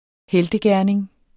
Udtale [ ˈhεldəˌgæɐ̯neŋ ]